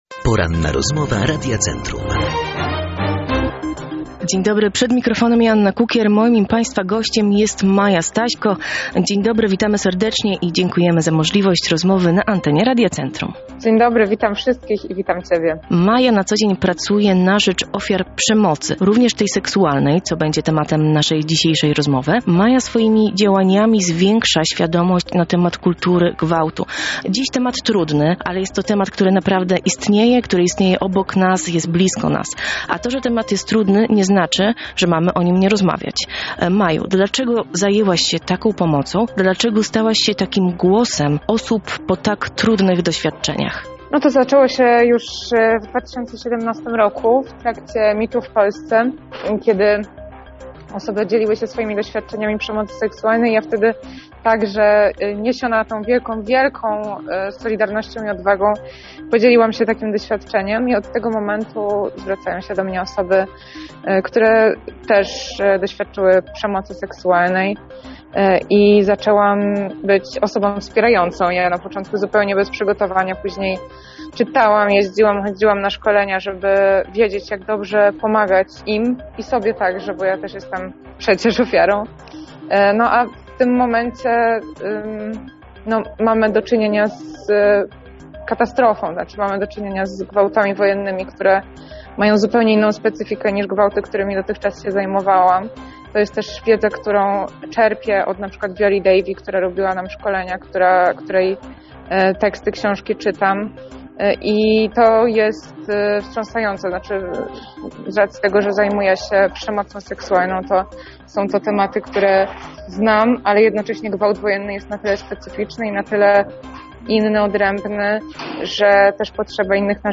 W Porannej Rozmowie Radia Centrum, Staśko wspomniała również o trudnej sytuacji kobiet w Ukrainie.
ROZMOWA